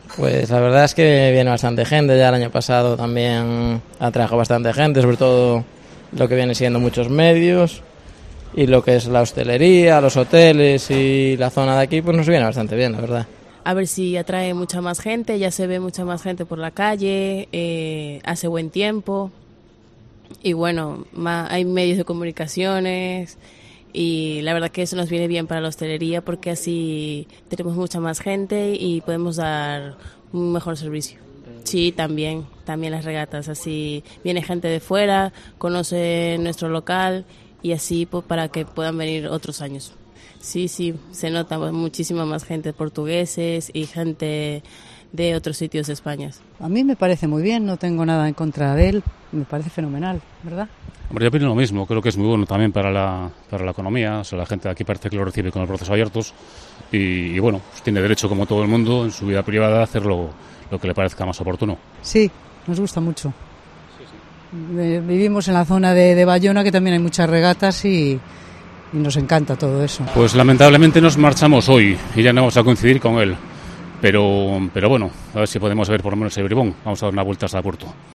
"Me gusta que venga, a mí me gusta el rey", "es más hasta para Sanxenxo le viene bien", "pues siempre también es una publicidad buena", explican algunos vecinos
Opiniones desde Sanxenxo ante la llegada de Juan Carlos I